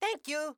Voice line from Mario saying "thank you" in Super Mario Bros. Wonder
Voice_Mario_GoalGreet_02_1.wav.mp3